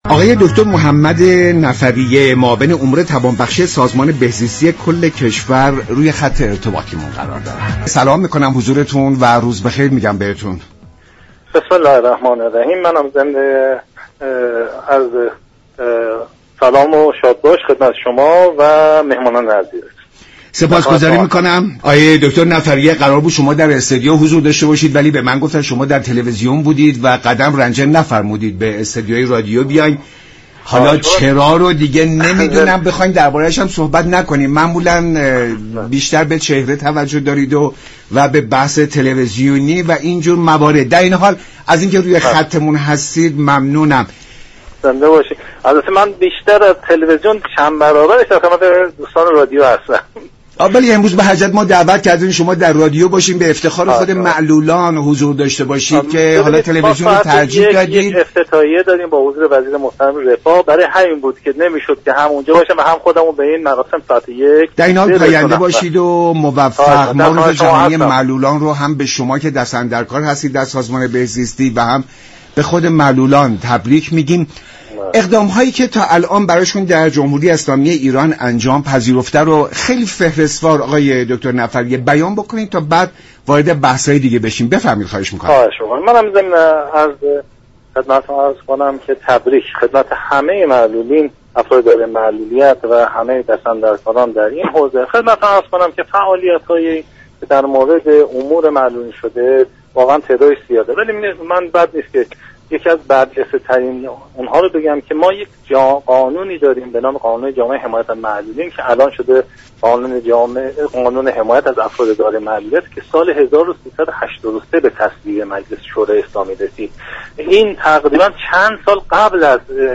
به گزارش شبكه رادیویی ایران، «محمد نفریه» معاون امور توانبخشی سازمان بهزیستی كشور در برنامه «ایران امروز» به شرح وضعیت معلولان و اقدامات انجام شده پرداخت و گفت: یكی از مهم ترین اقدامات صورت گرفته در این حوزه، تصویب قانون حمایت از افراد دارای معلولیت بوده است.